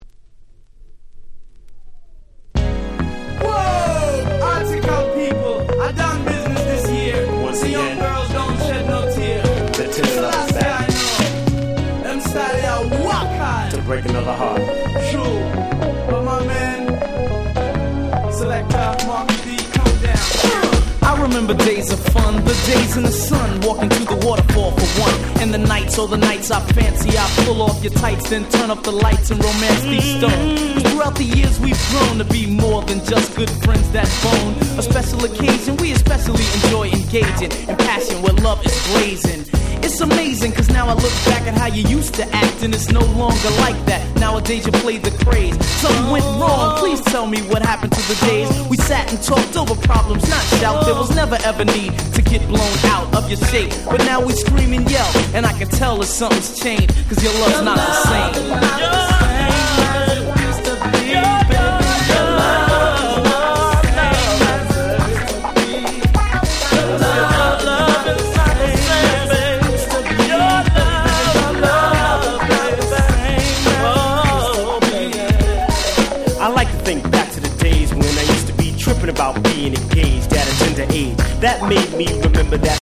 91' Very Nice Hip Hop / New Jack Swing / R&B Album !!
エフエスエフェクト NJS 90's ニュージャックスウィング